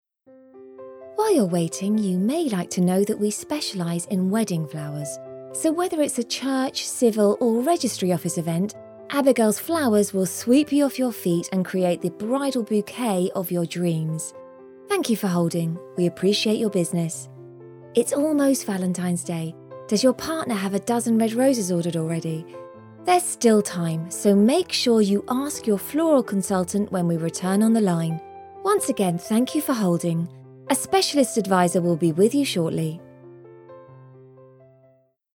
A lively British female voice
IVR
British general
Middle Aged